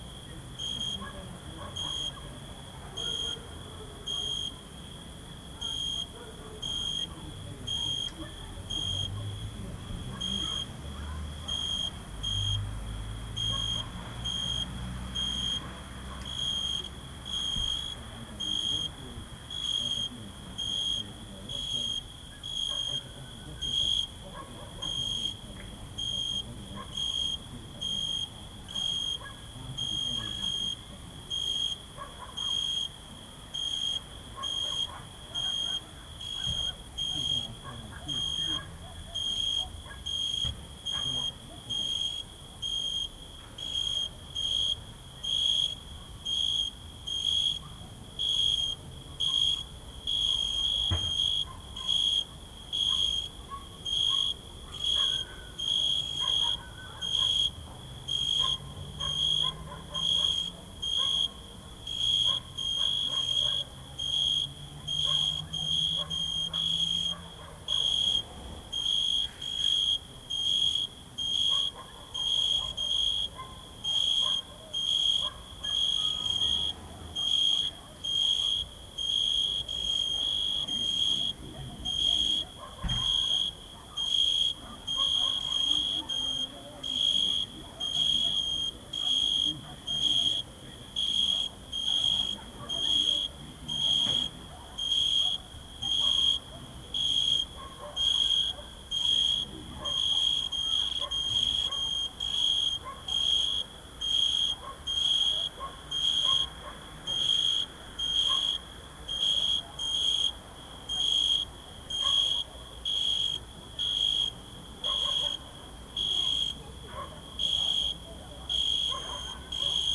Category 🌿 Nature
cricket crickets field-recording meditation nature night purist relax sound effect free sound royalty free Nature